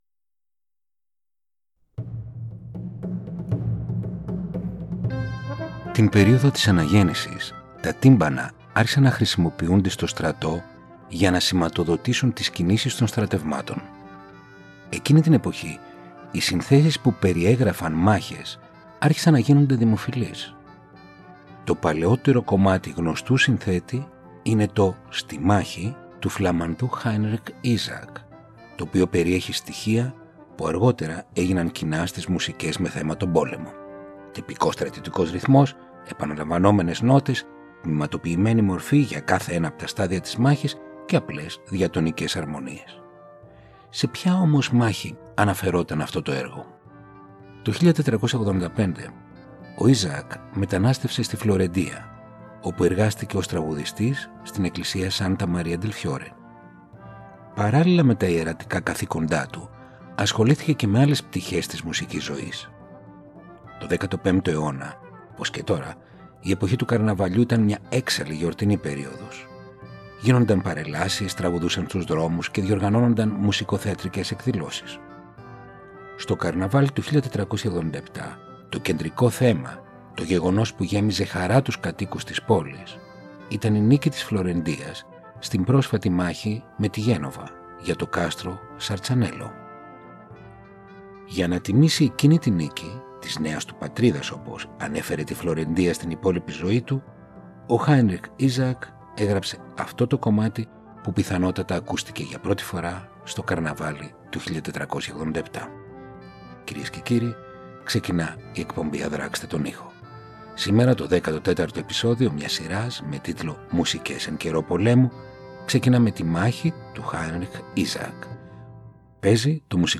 Μουσική εν καιρώ πολέμου επεισόδιο 14ο ” χίλιες φορές πεθαίνω